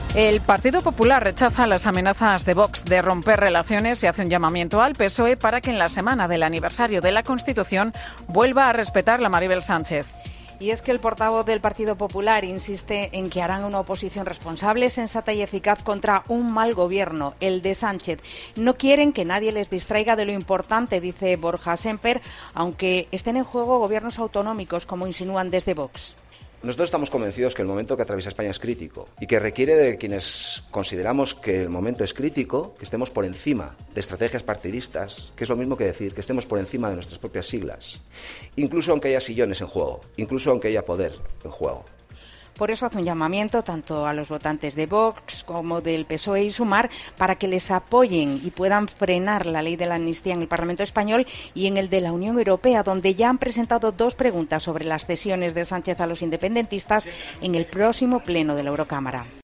"Nosotros estamos convencidos de que el momento que atraviesa España es crítico y que requiere de quienes consideramos que el momento es crítico que estemos por encima de estrategias partidistas, que es lo mismo que decir que estemos por encima de nuestras propias siglas, incluso, aunque haya sillones en juego, incluso, aunque haya poder en juego", ha dicho en rueda de prensa.